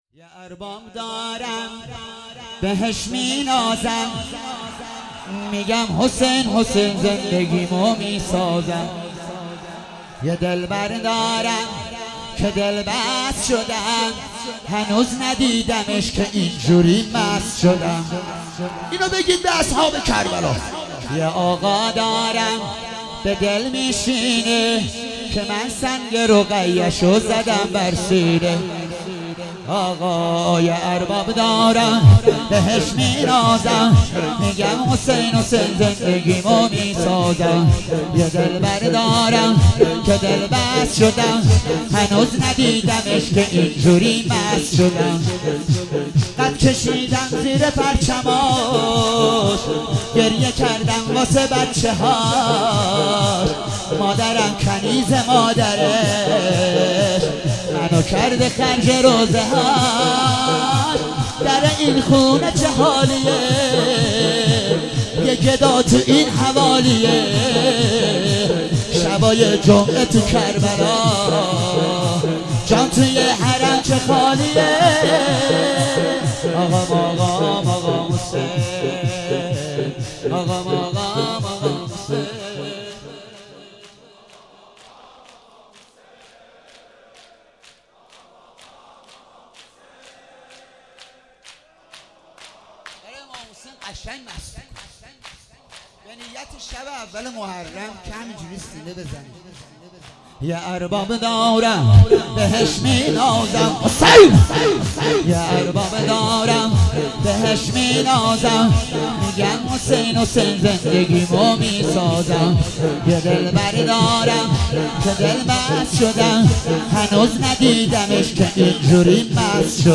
شب 19 ماه رمضان 96 _شور_یه ارباب دارم بهش مینازم
مداحی